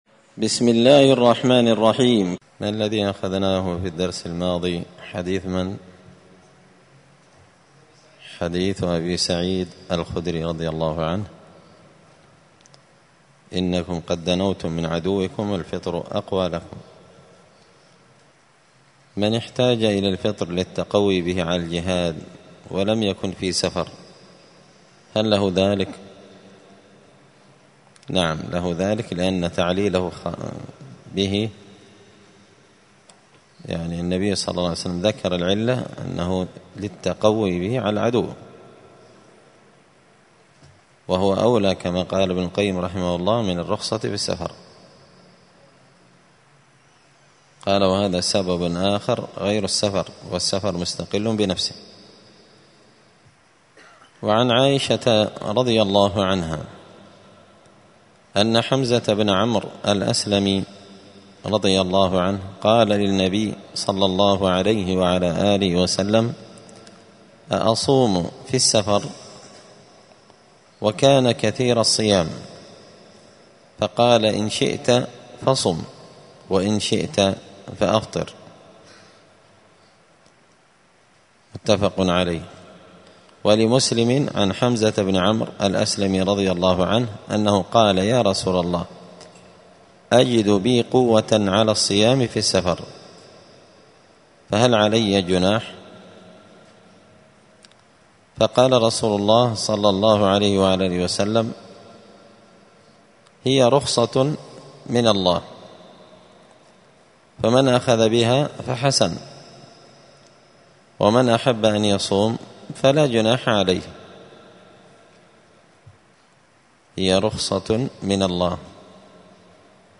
دار الحديث السلفية بمسجد الفرقان بقشن المهرة اليمن